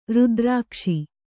♪ rudrākṣi